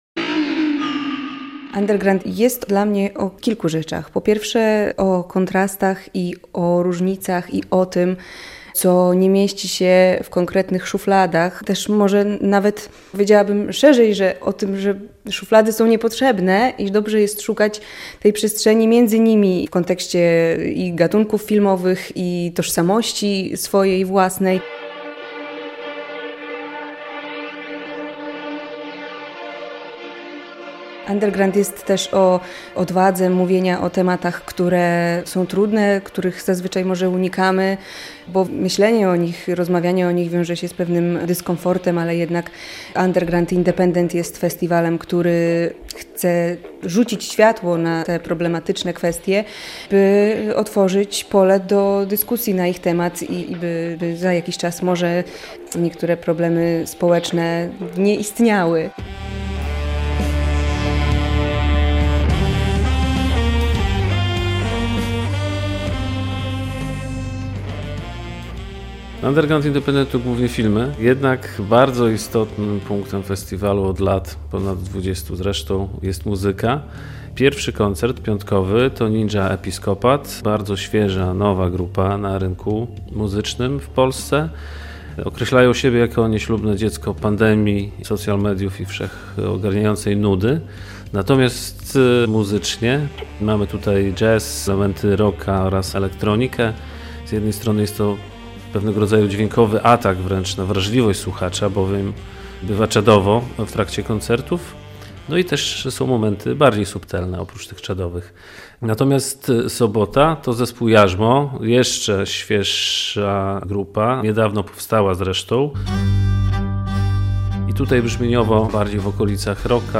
Zapowiedź Festiwalu Underground/Independent - relacja